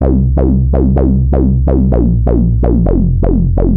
Old Skool_127_C.wav